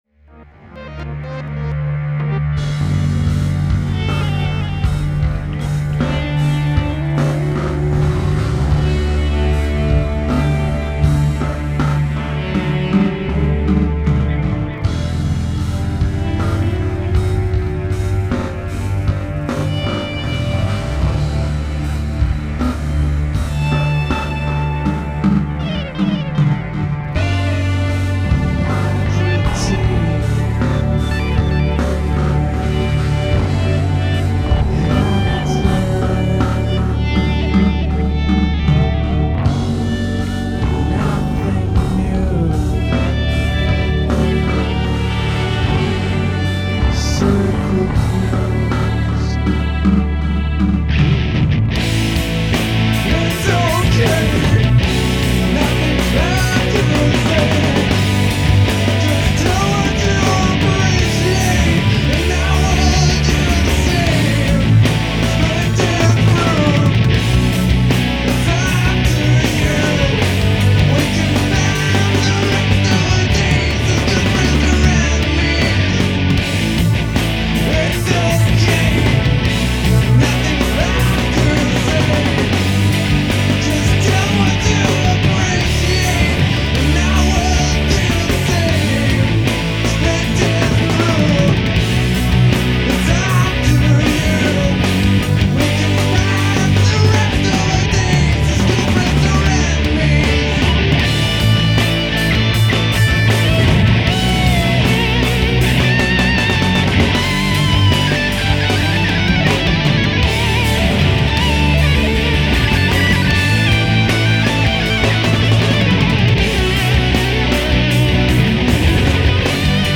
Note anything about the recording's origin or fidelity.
tracks 1, 2, 4, 17 - 25 remastered